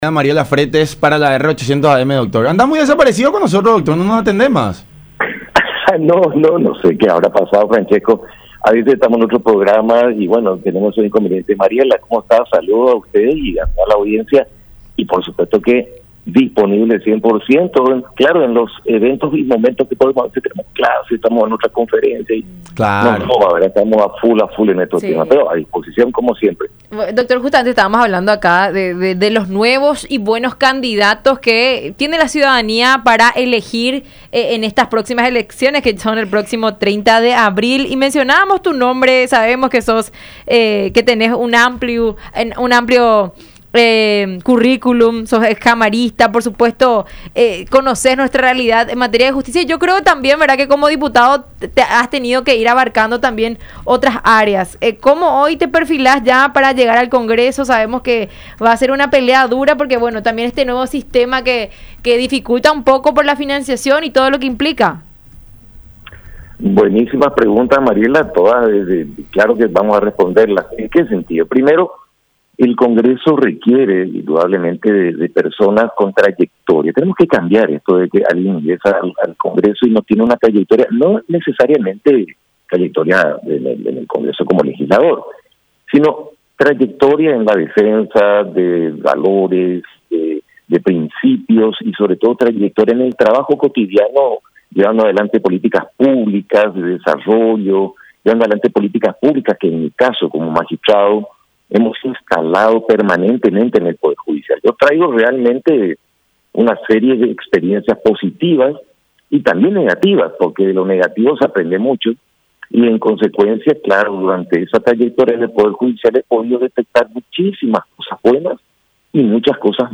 Entre esas cosas malas he detectado muchísimas anomalías entre fiscales, policías, jueces en investigaciones y hay tanto por mejorar en las normativas”, dijo Martínez en conversación con La Unión Hace La Fuerza por Unión TV y radio La Unión.